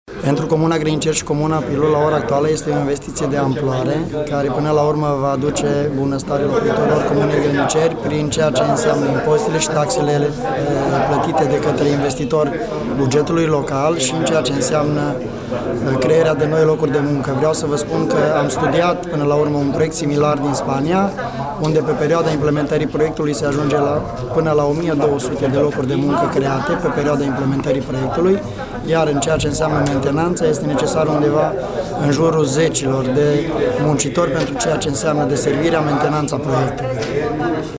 Claudiu Petru Bătrânuț, primar la Grăniceri:
Claudiu-Batranut-primar.mp3